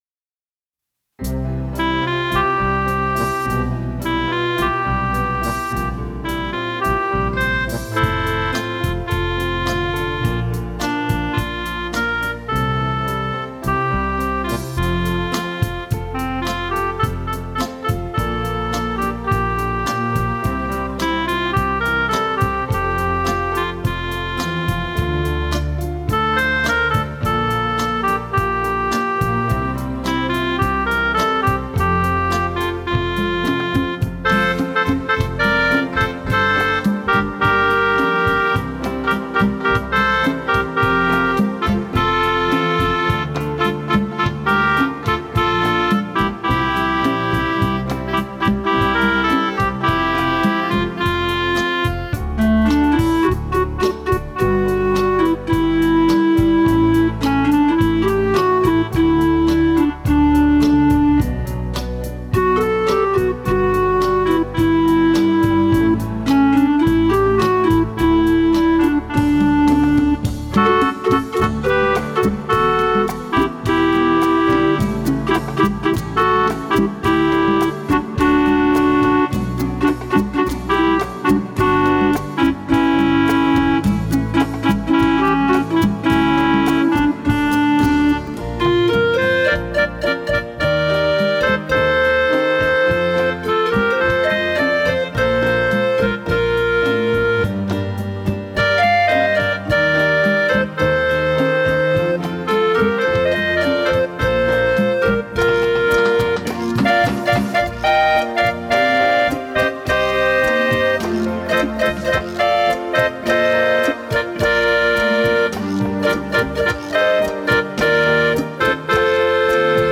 restored legacy synthesizer